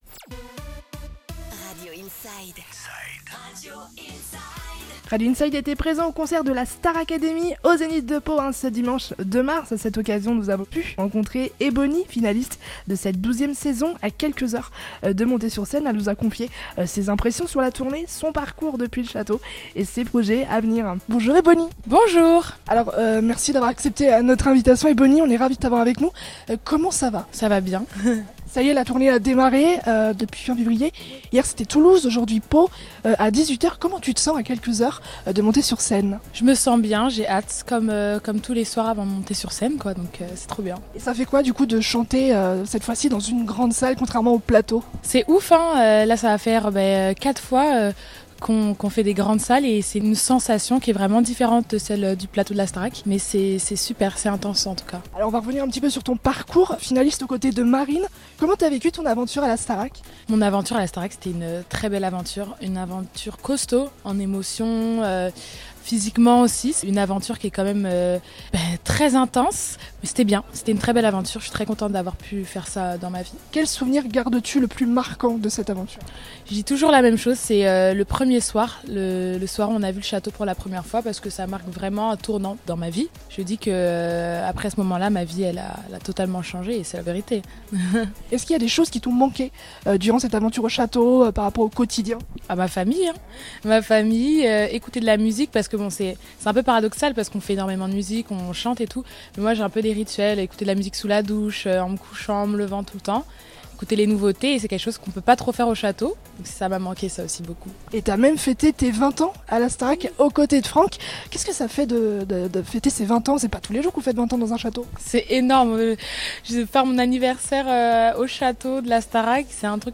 Interview d'Ebony à l'occasion du Concert de la Star Academy au Zénith de Pau !
Ebony, finaliste de la Star Academy saison 12, était notre invitée à l'occasion du concert de la Star Academy qui s'est déroulé dimanche 2 mars au Zénith de Pau.